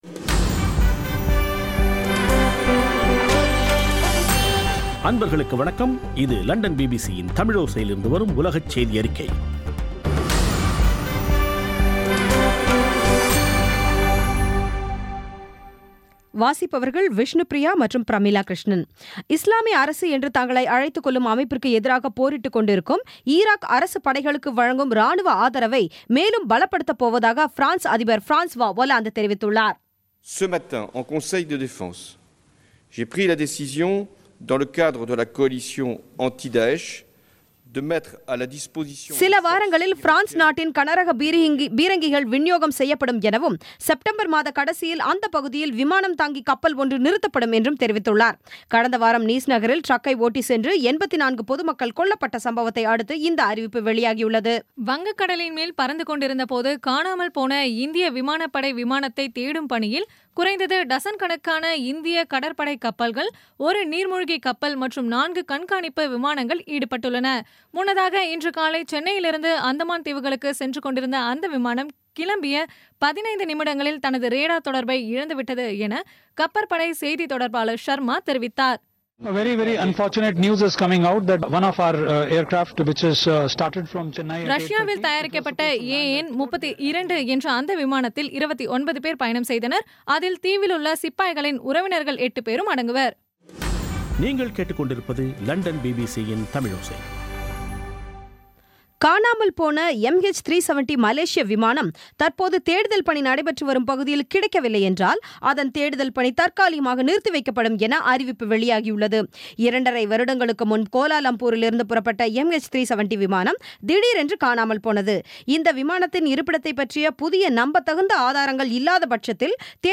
பிபிசி தமிழோசை செய்தியறிக்கை (22.07.2016)